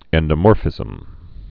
(ĕndə-môrfĭzəm)